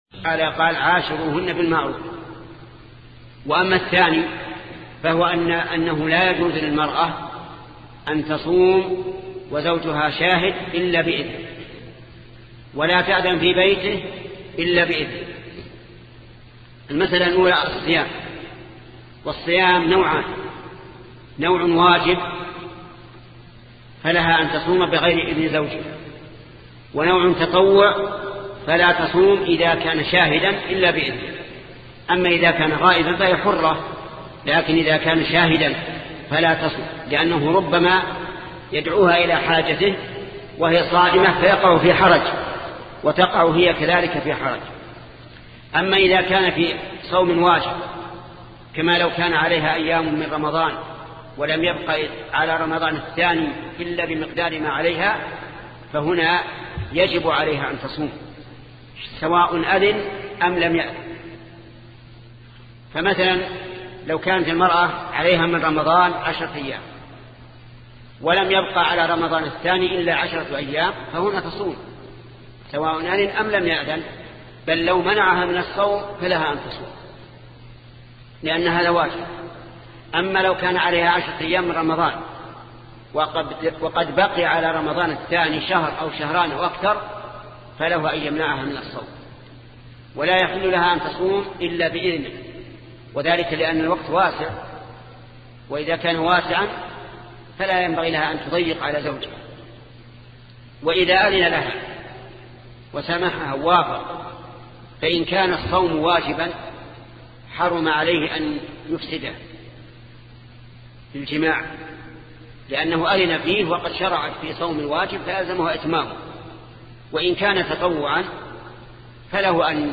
سلسلة مجموعة محاضرات شرح رياض الصالحين لشيخ محمد بن صالح العثيمين رحمة الله تعالى